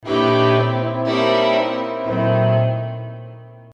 Fig. 4.2. La stridente cadenza finale di uno «Scherzo Musicale», K522 di Mozart.